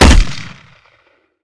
gp30_grenshoot.ogg